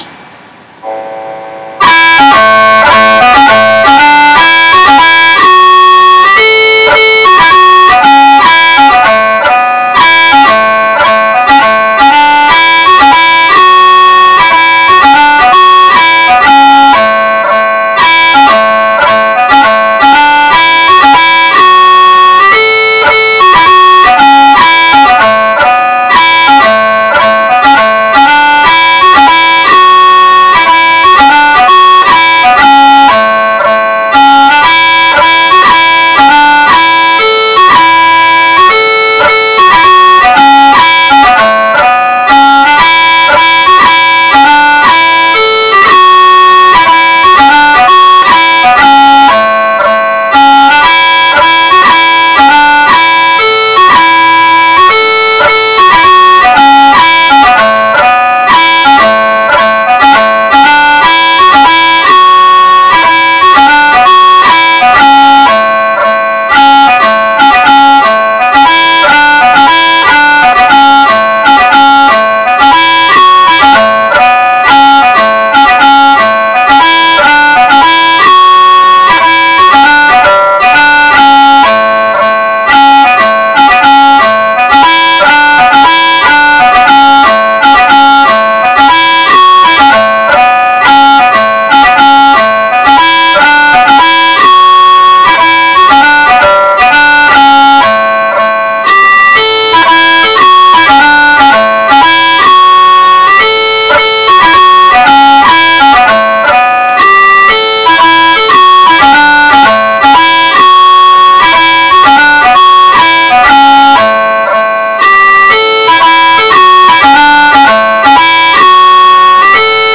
2/4 march
I’ve attached a PDF and an MP3 of the tune (the MP3 is just a recording from the software but it gives an Idea of how it goes).